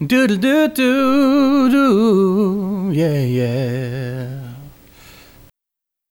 Track 13 - Vocal Do Do Do.wav